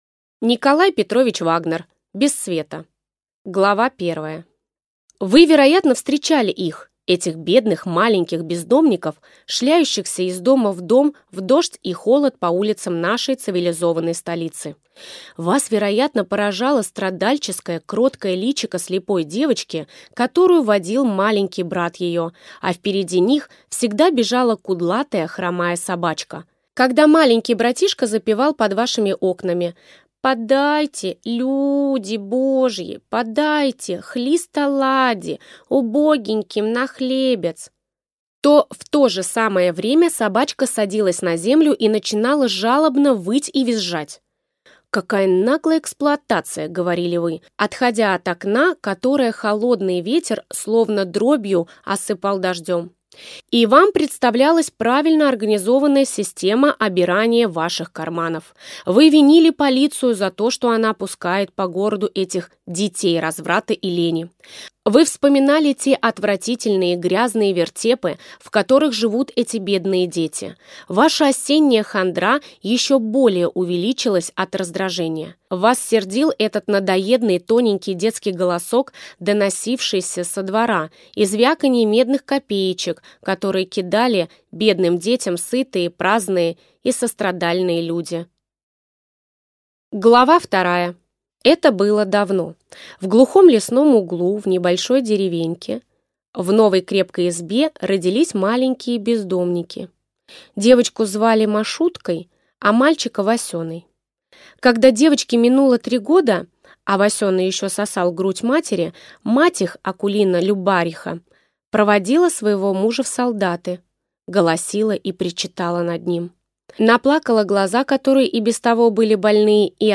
Аудиокнига Без света | Библиотека аудиокниг
Прослушать и бесплатно скачать фрагмент аудиокниги